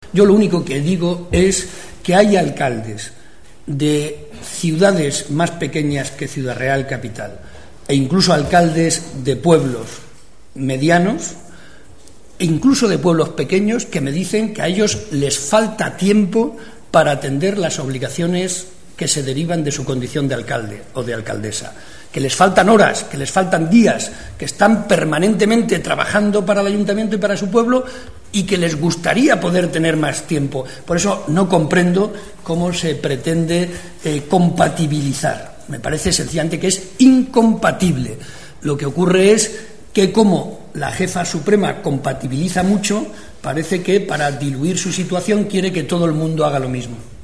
Cortes de audio de la rueda de prensa
Audio-Barreda-listas-PP.mp3